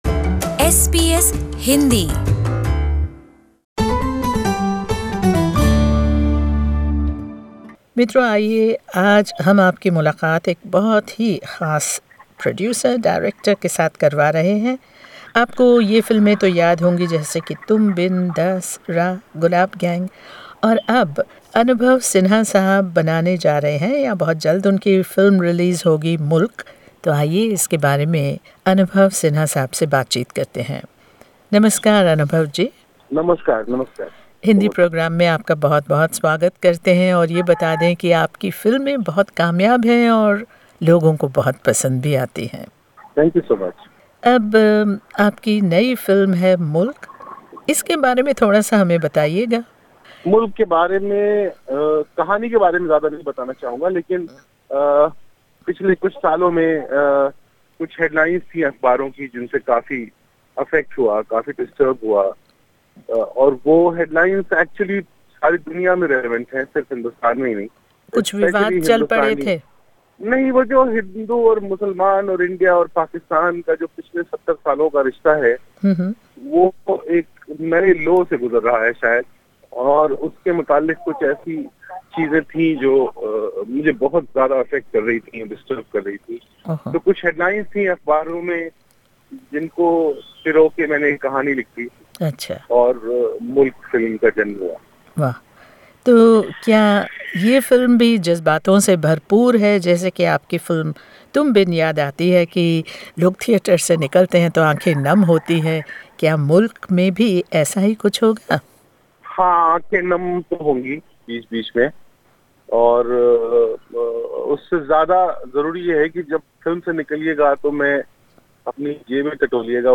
Tune in to hear this exclusive interview with Anubhav Sinha by SBS Hindi...